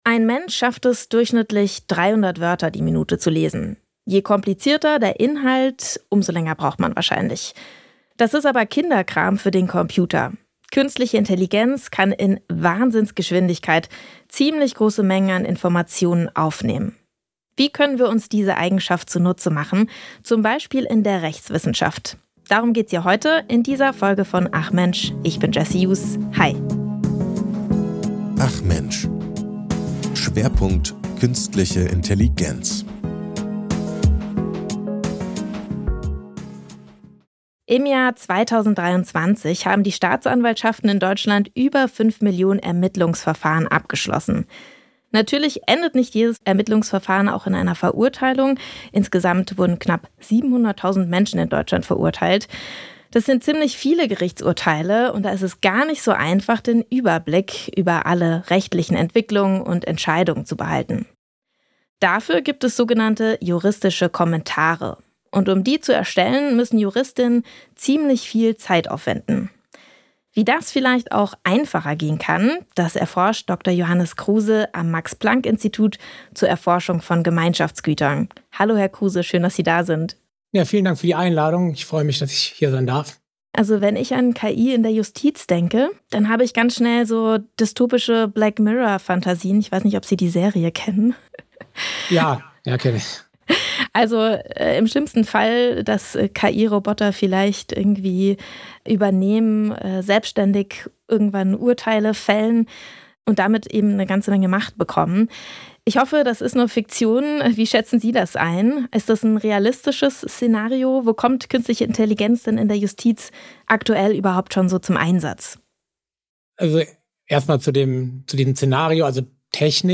Ein Gespräch über Bias, Halluzinationen und die Frage, ob Sprachmodelle bessere Juristen sind als Menschen.